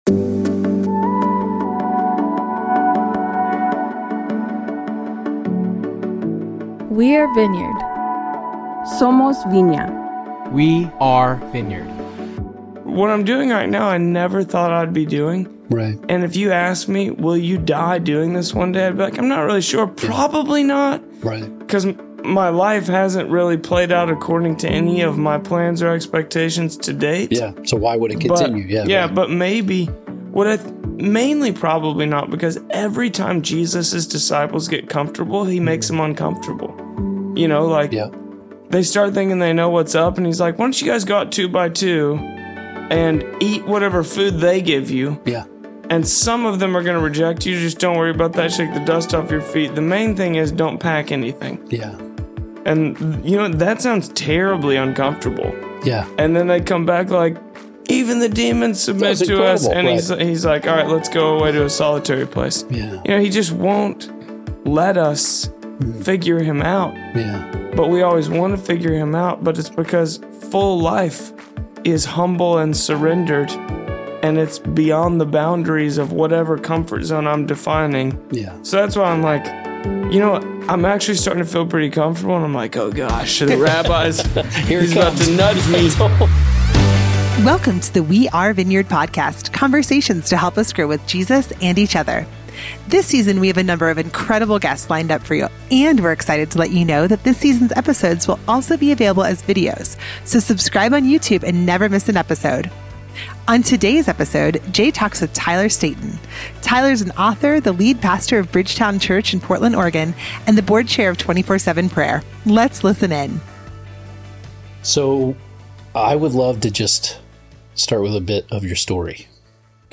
We’ll see you next week for part two of this conversation!